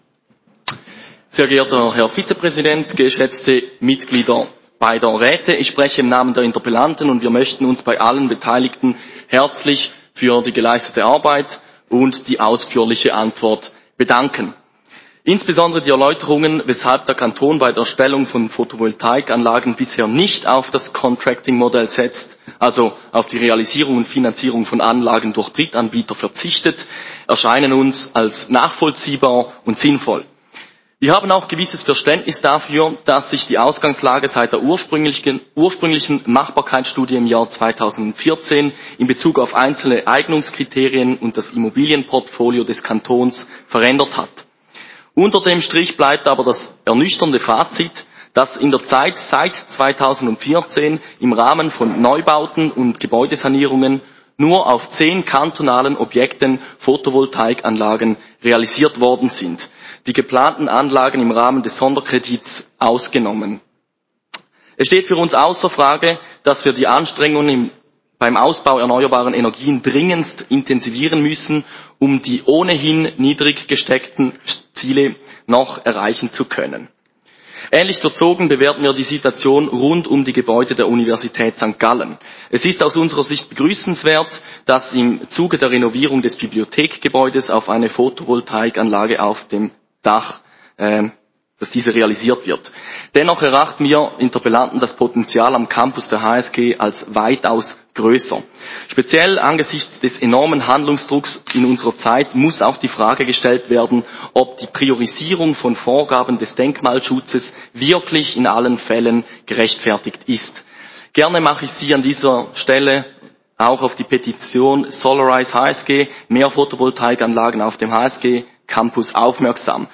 29.11.2021Wortmeldung
Session des Kantonsrates vom 29. November bis 1. Dezember 2021